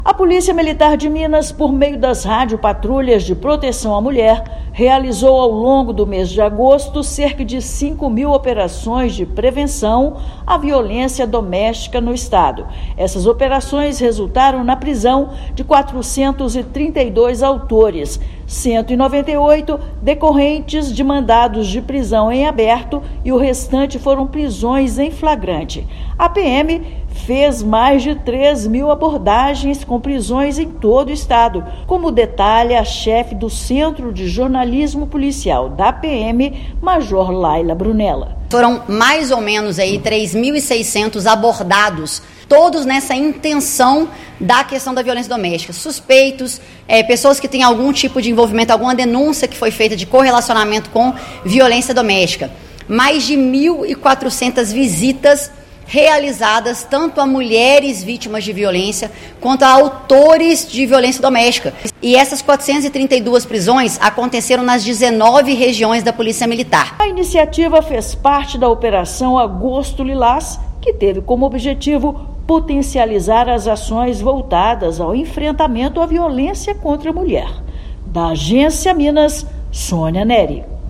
Prisões são resultados da Operação Agosto Lilás, que teve como objetivo potencializar as ações voltadas ao enfrentamento à violência contra a mulher. Ouça matéria de rádio.